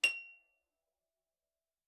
KSHarp_F7_f.wav